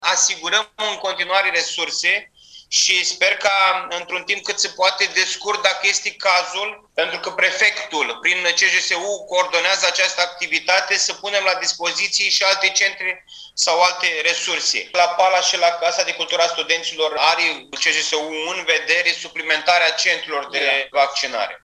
Președintele Consiliului Județean Iași, Costel Alexe a declarat, astăzi, într-o conferință de presă că vor fi suplimentate fluxurile de vaccinare de la Palas, Casa Studenților și de la Gară: